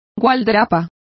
Complete with pronunciation of the translation of caparison.